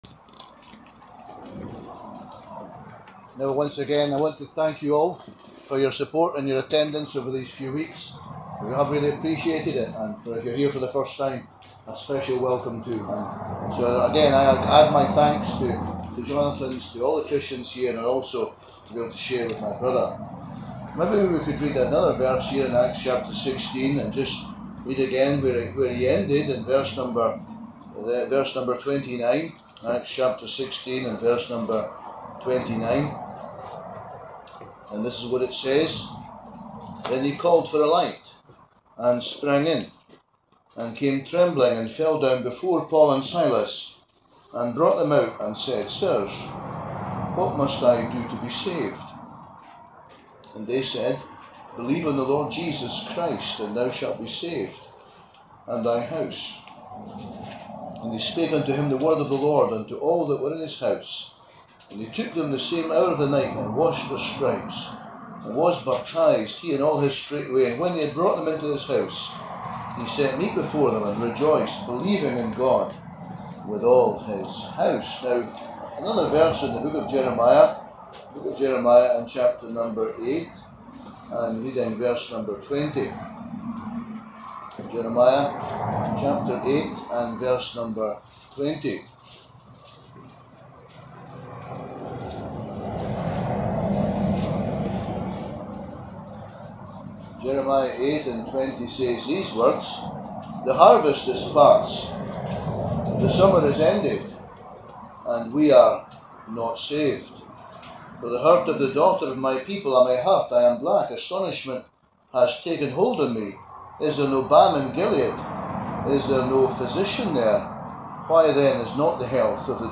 A very challenging and earnestly preached message of salvation.